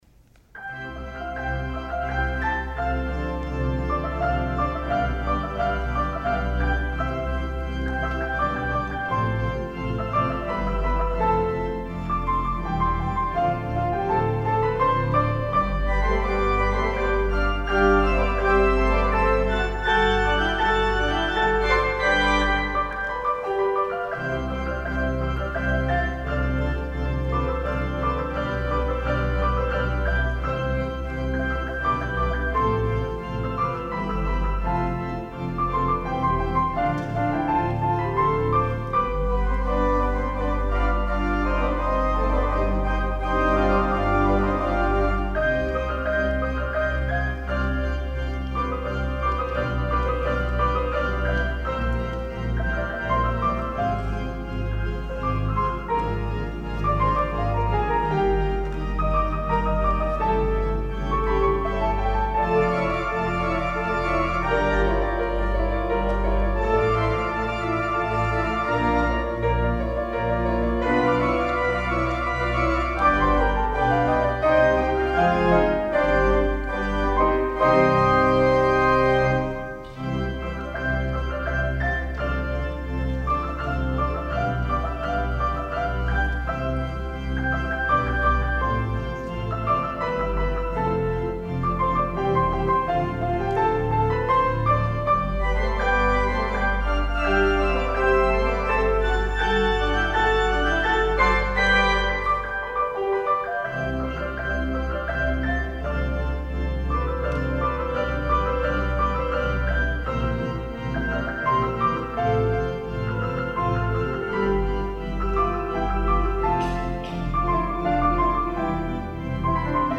piano
organ